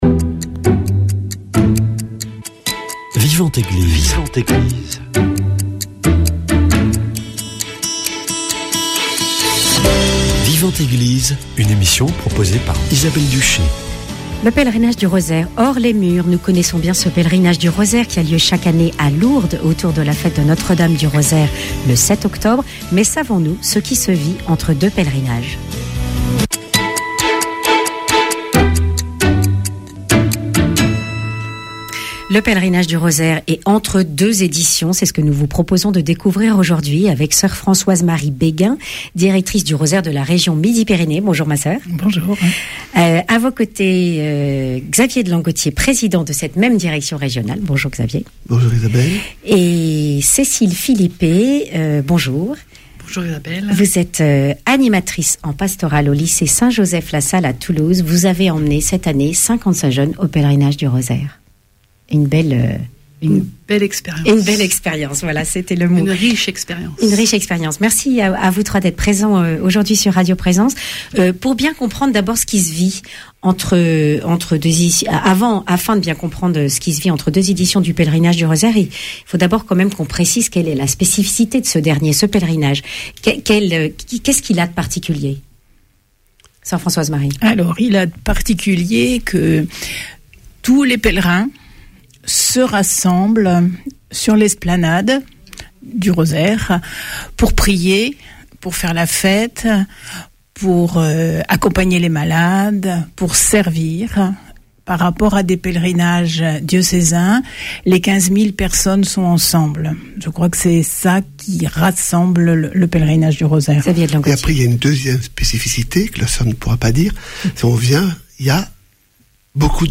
Témoignages